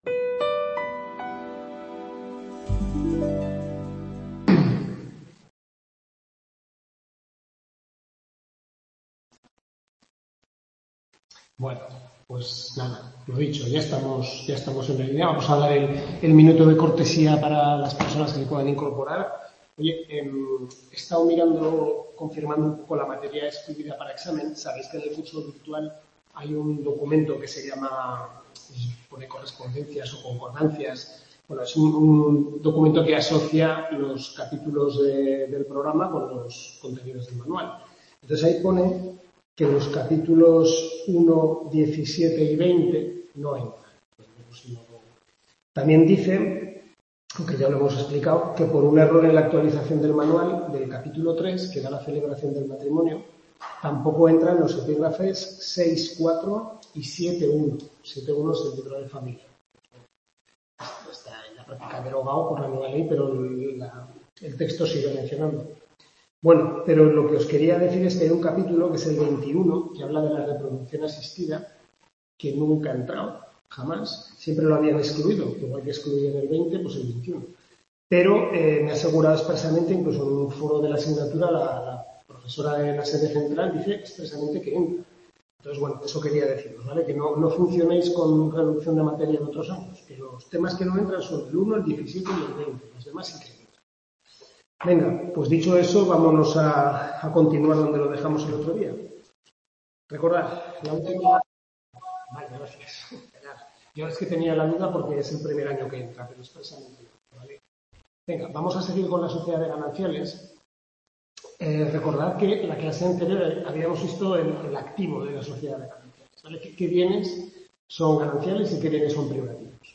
Tutoría 3/5 Derecho de Familia